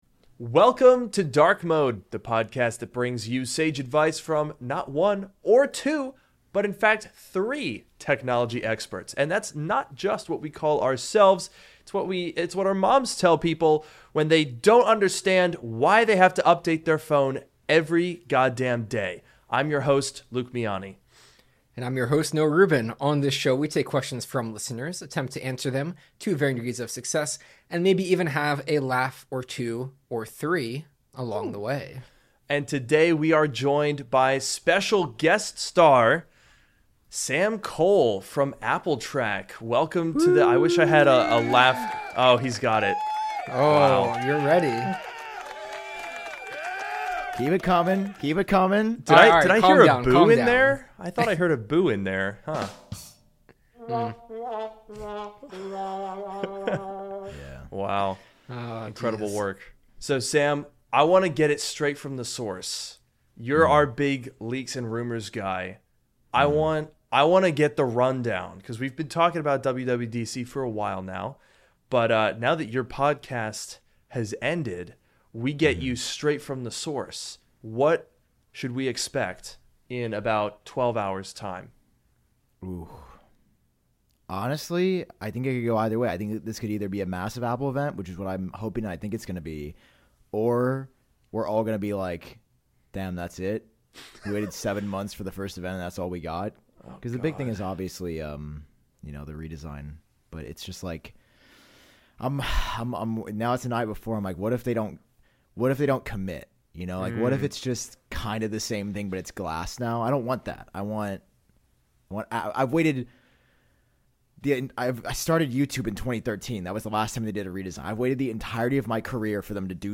This is Drk Mode, the podcast that brings you sage advice from two technology experts.